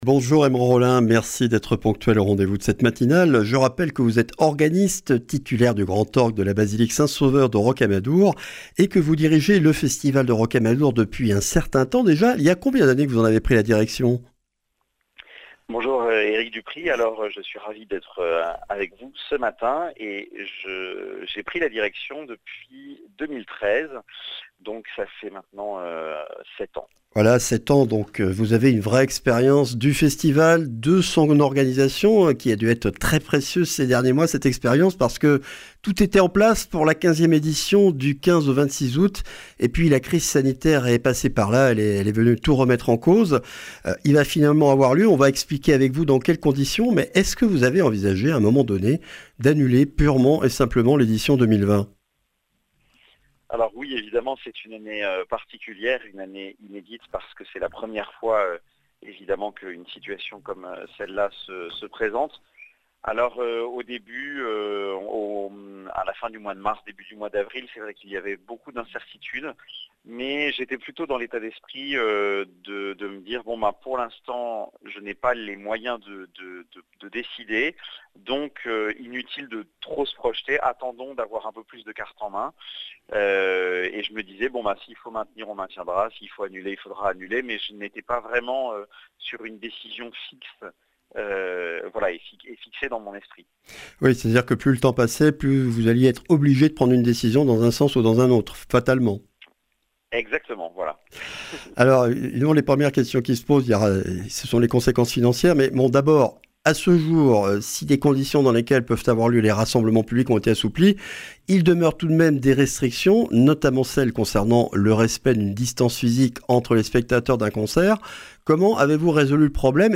Accueil \ Emissions \ Information \ Régionale \ Le grand entretien \ Le Festival de Rocamadour est maintenu !